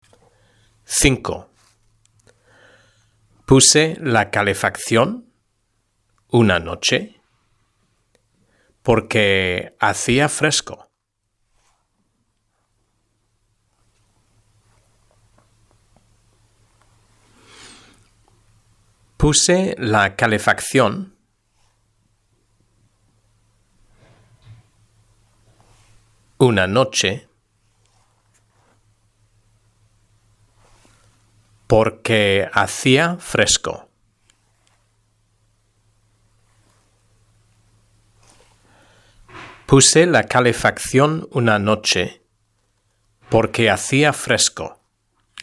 3.1 Los viajes y el turismo: Dictado #1 (H) – SOLUCIÓN